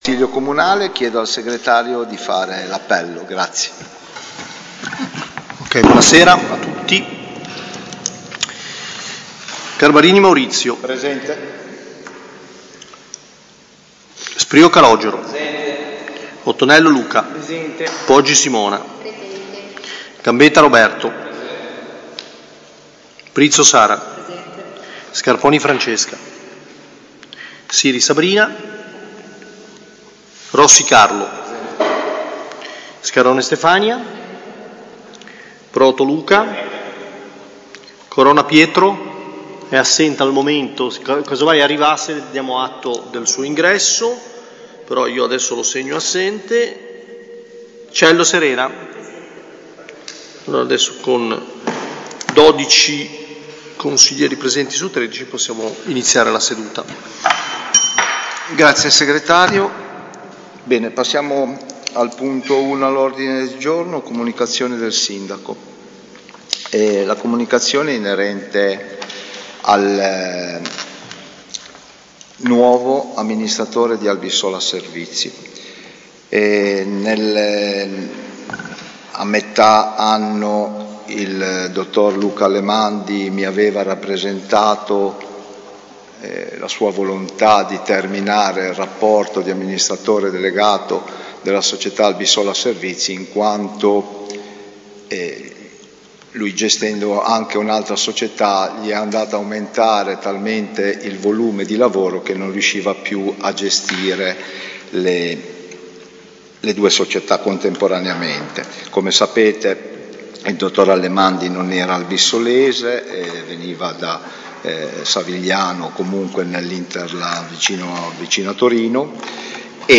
Seduta del Consiglio comunale giovedì 28 agosto 2025, alle 21.00, presso l'Auditorium comunale in via alla Massa. In seduta ordinaria sono stati trattati i seguenti argomenti: Comunicazione del Sindaco.